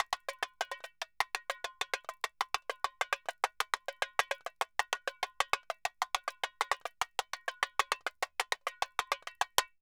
Tamborin 1_Samba 100_1.wav